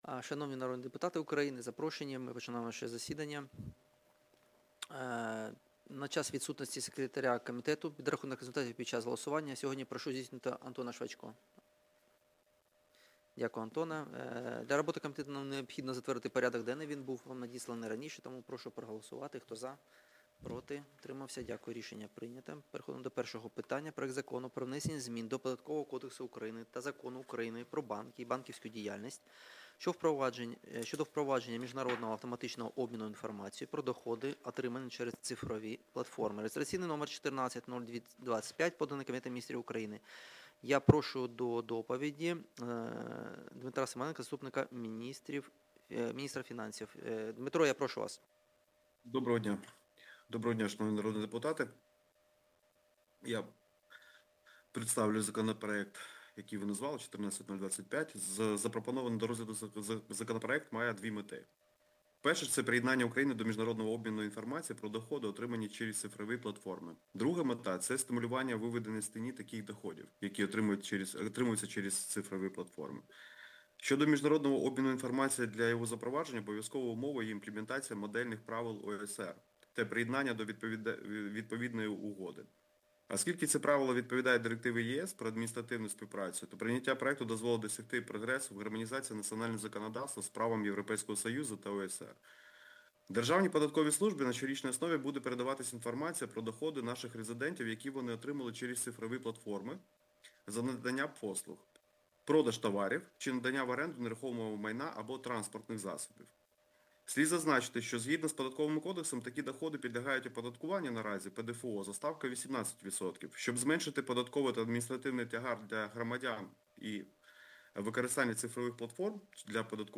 Аудіозапис засідання Комітету від 18.11.2025